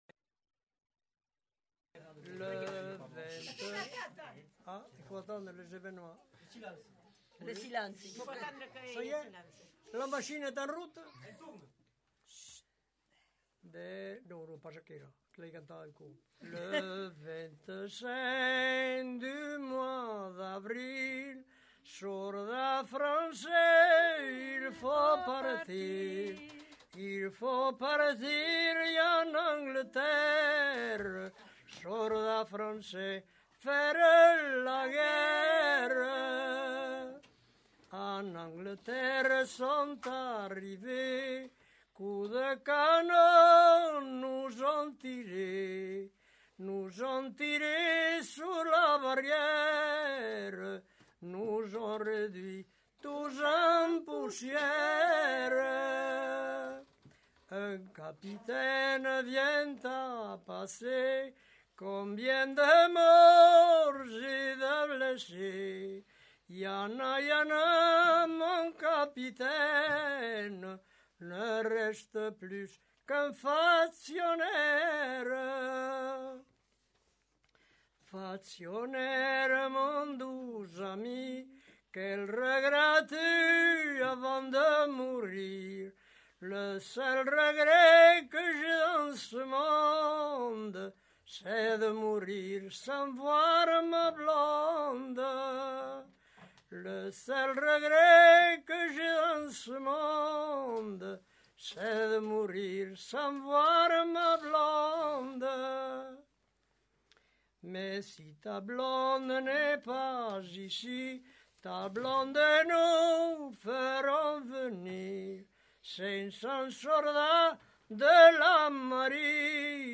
Aire culturelle : Bigorre
Lieu : Villemur-sur-Tarn
Genre : chant
Type de voix : voix d'homme
Production du son : chanté